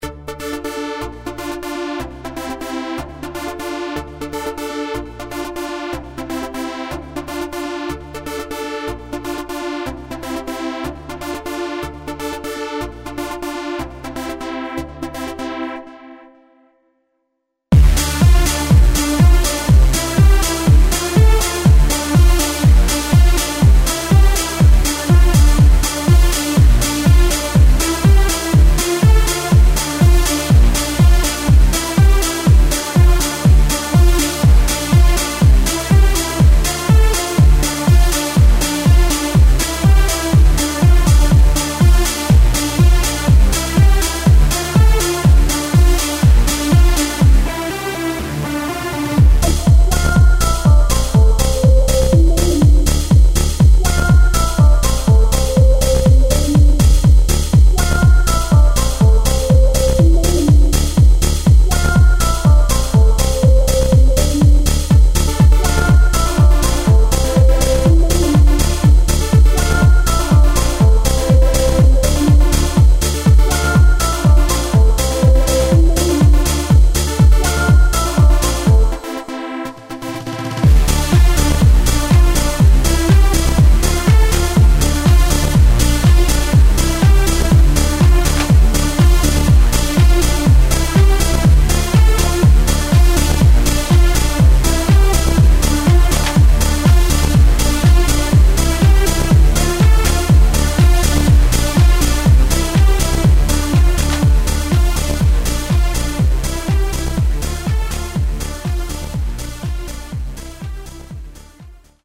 Melodic dance sequence